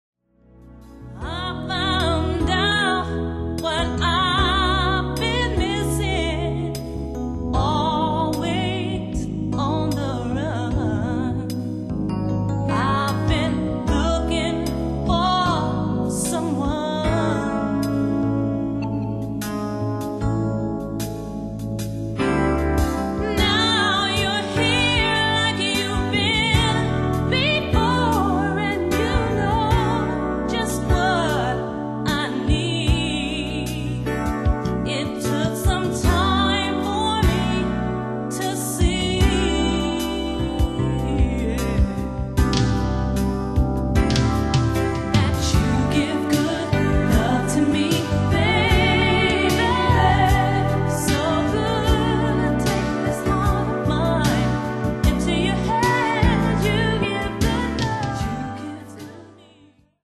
Multiplex (mit und ohne Gesang)